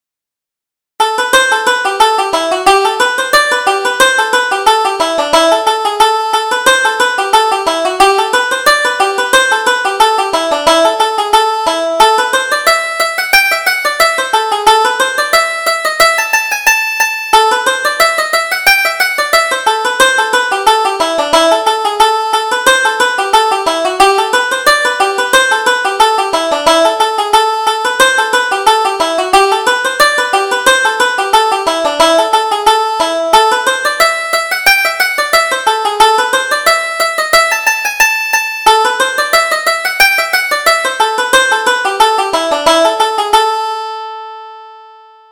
Reel: Touch Me If You Dare - 2nd Setting